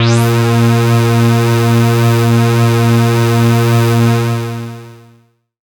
37ag01syn-a#.wav